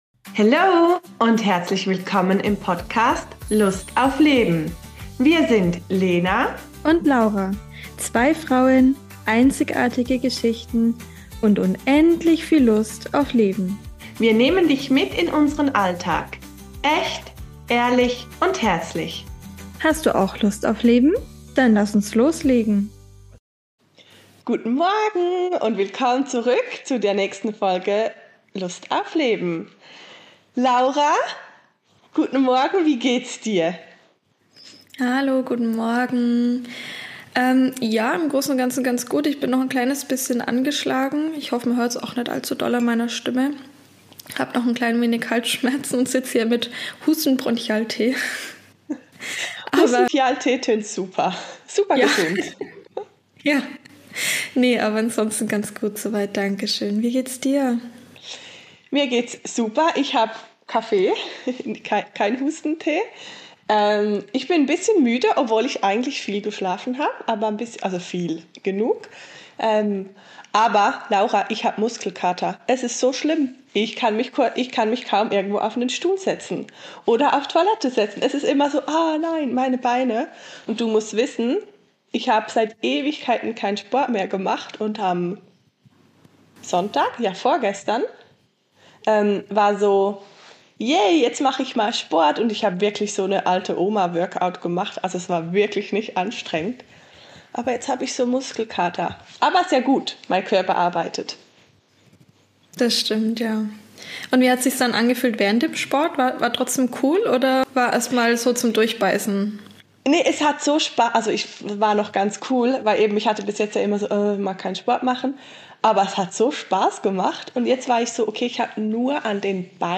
Diese Folge ist wie ein gemütlicher Kaffeeklatsch auf dem Sofa – nur ohne Sofa (hör rein, dann weißt du, warum ).